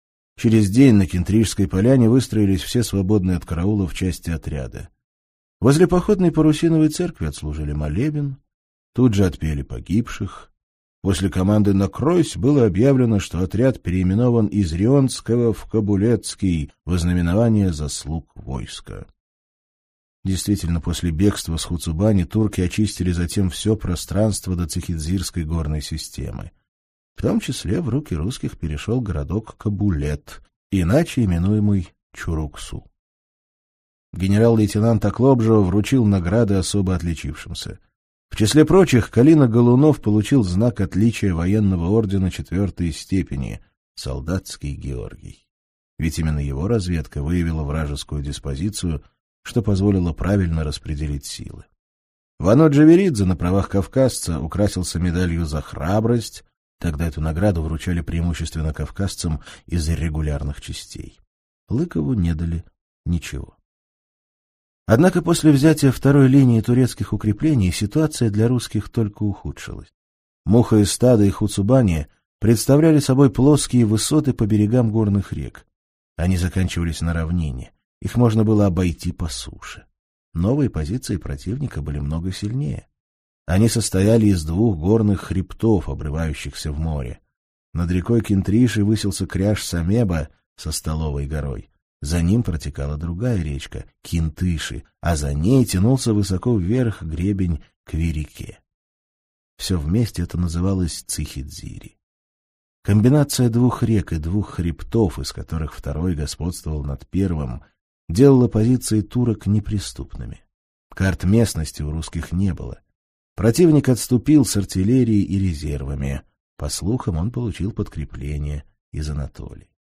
Аудиокнига Удар в сердце (сборник) - купить, скачать и слушать онлайн | КнигоПоиск